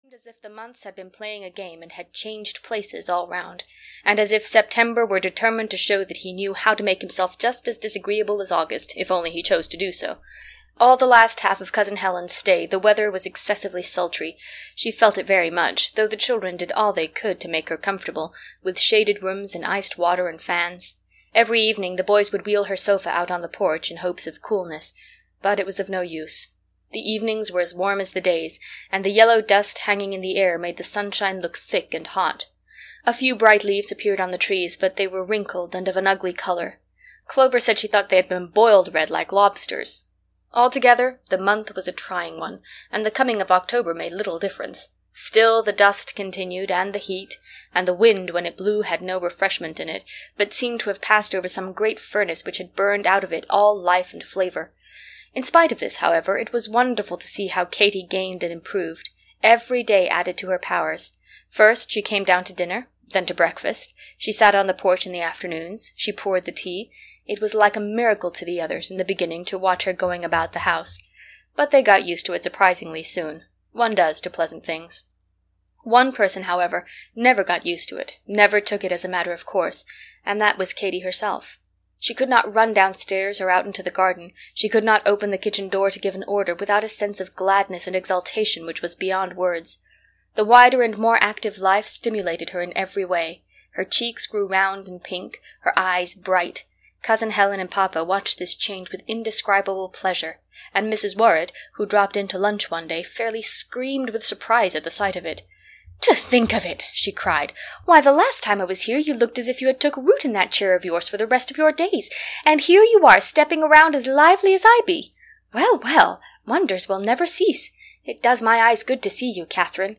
What Katy Did at School (EN) audiokniha
Ukázka z knihy